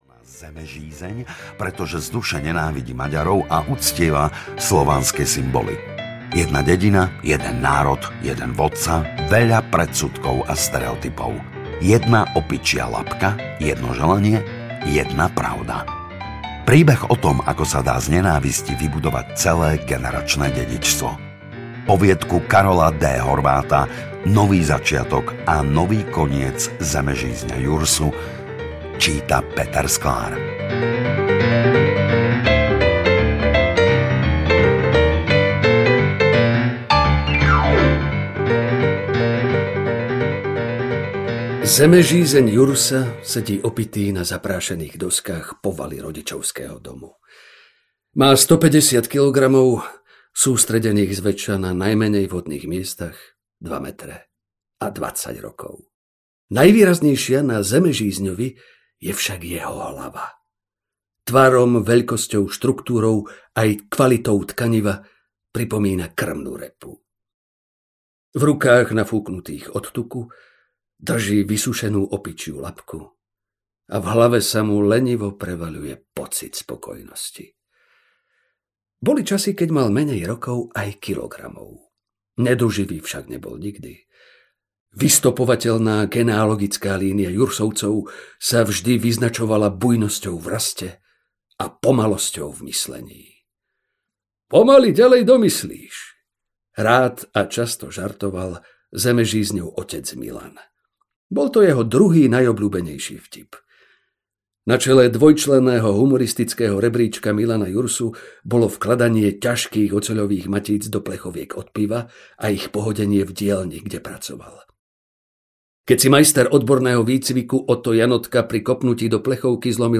Audio knihaSlovensko Tajomné
Ukázka z knihy
Dvadsaťdeväť autorov vám postupne predstaví svoju víziu tajomna. Hlas ich príbehom prepožičali známi slovenskí herci, zážitok umocňuje na mieru namiešaná hudba.